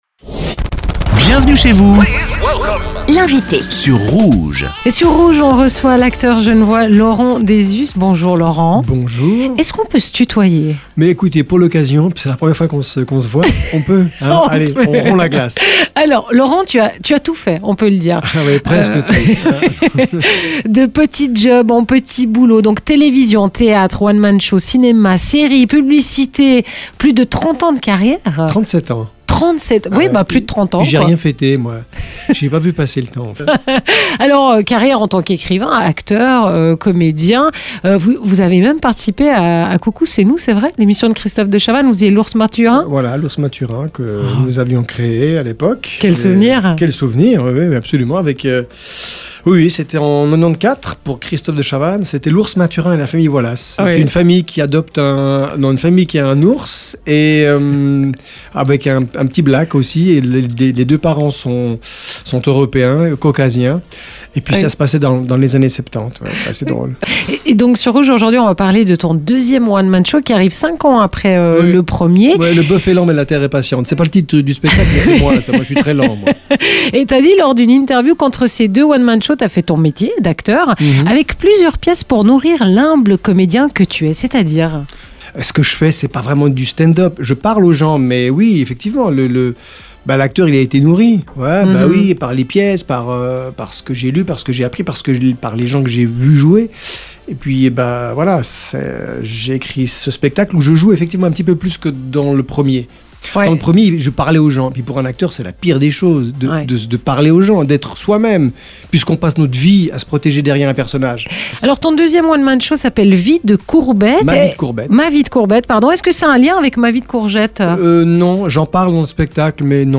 Interview 1ère partie